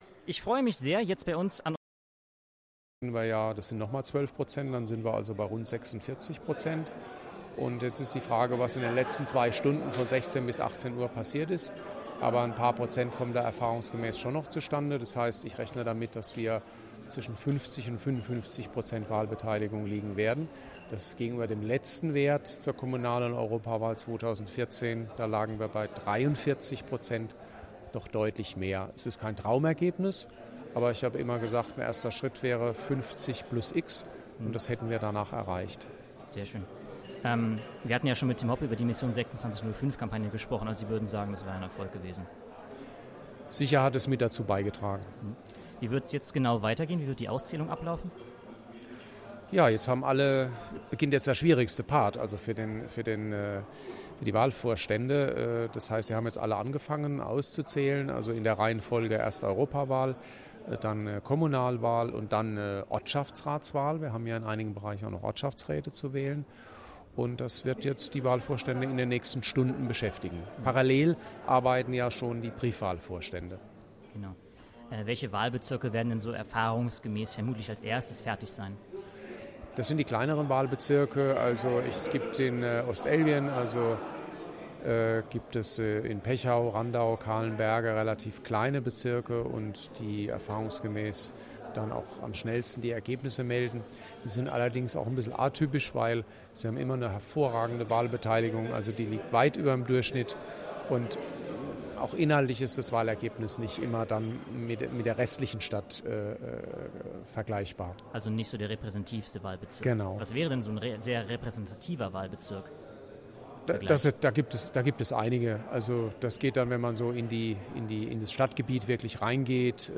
Live-Interview am Wahlabend mit Holger Platz (Stadtwahlleiter) – h²radio